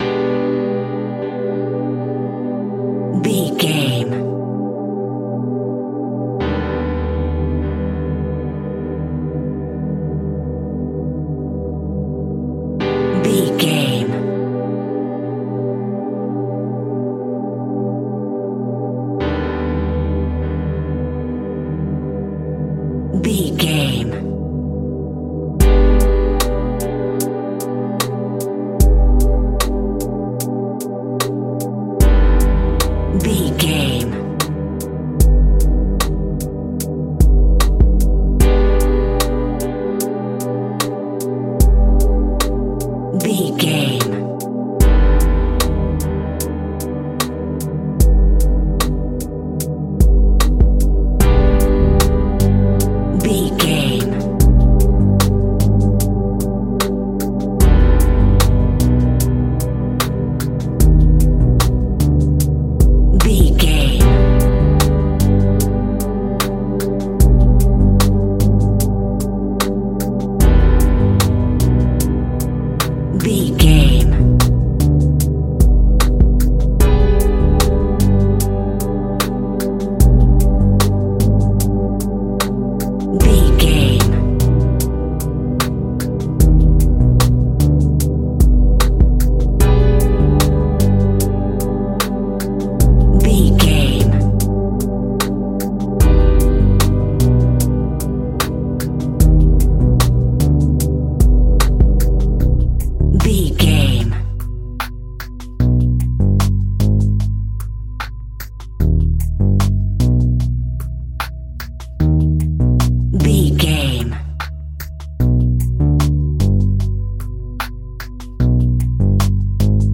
Zombie Chilling Music Cue.
Diminished
scary
ominous
dark
suspense
haunting
eerie
piano
synthesiser
drums
drum machine
Horror synth
Horror Ambience